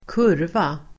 Uttal: [²k'ur:va]